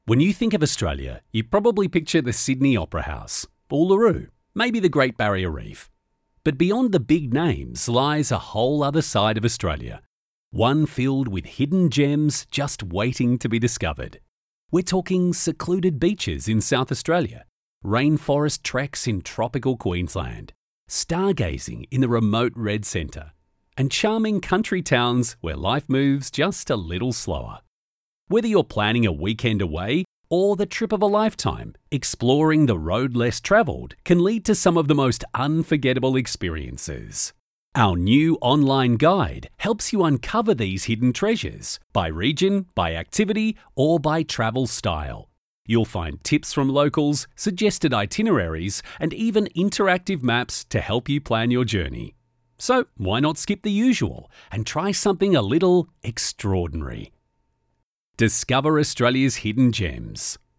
Male
English (Australian)
Warm and very flexible. Hard sell to natural with a great ability to bring the script to life.
Documentary
Words that describe my voice are Warm, Natural, Friendly.
1013Documentary.mp3